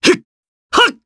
Clause-Vox_Skill2_jp.wav